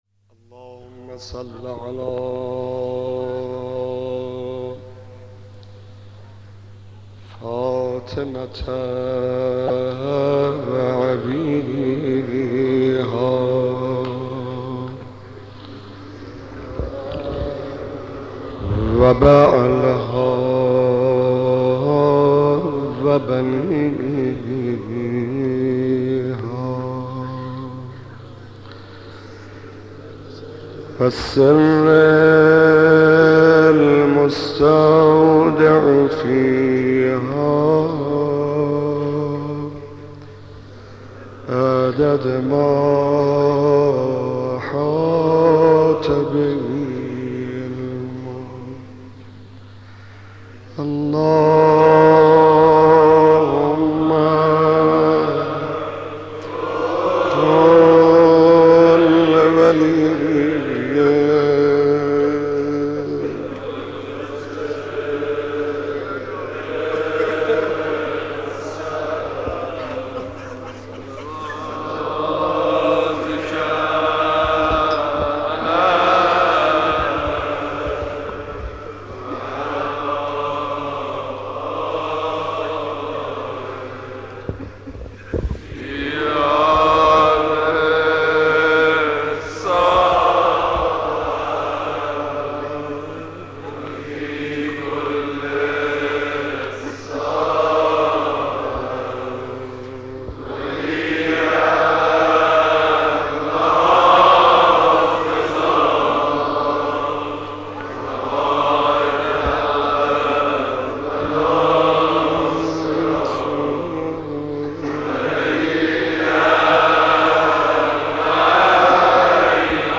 مناسبت : شب سوم محرم